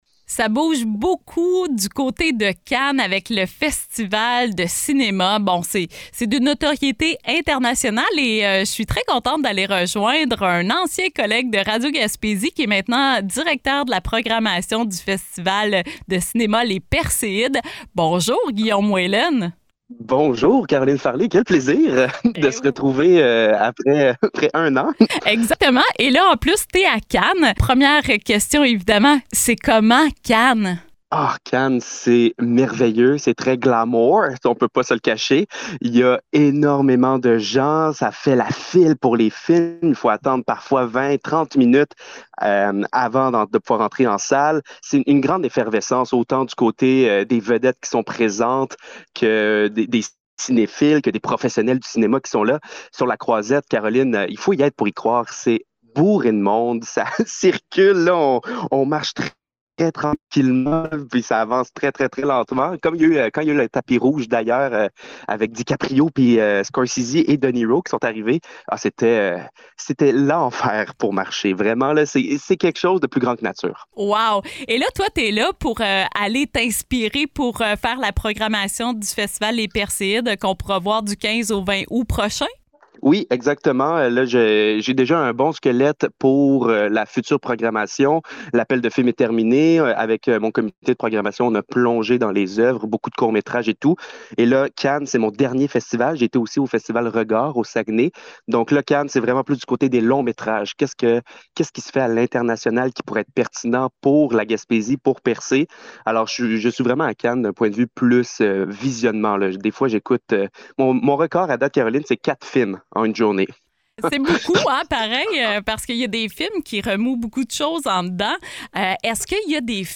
en direct de Cannes